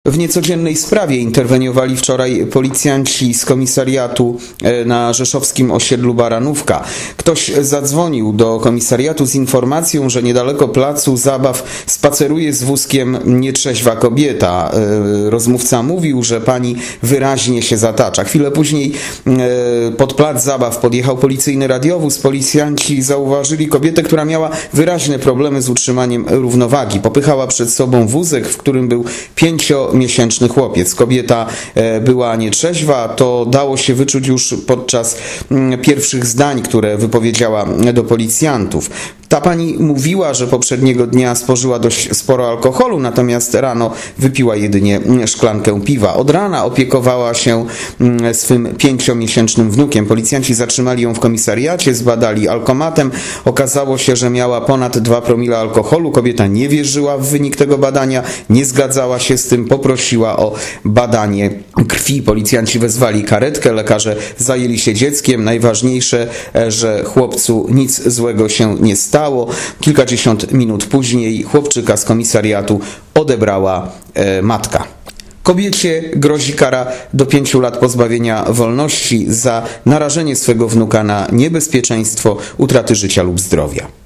Mówi podkom.